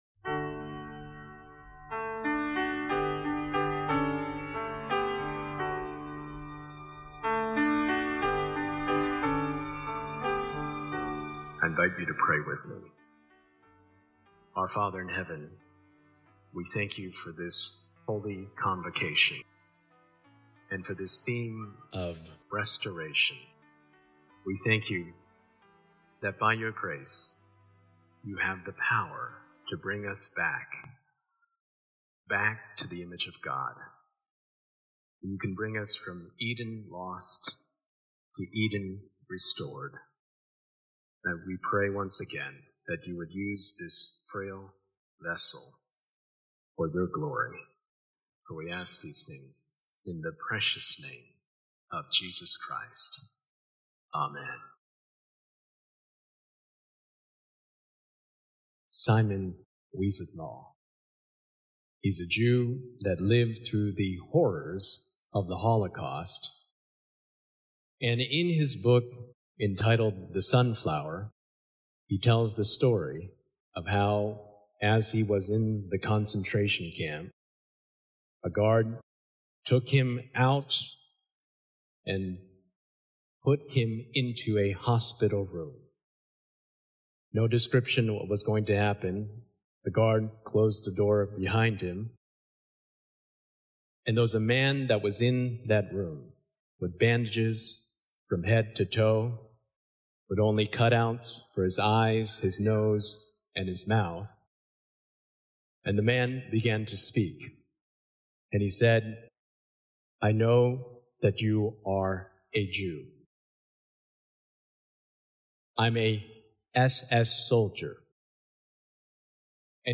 This sermon reveals forgiveness as a powerful work of God’s grace—one that restores hearts, heals deep wounds, and reflects the mercy of Christ on the cross. Through Scripture, real-life stories, and practical clarity, it shows how forgiveness frees us from bitterness and invites divine restoration, even when forgiveness feels humanly impossible.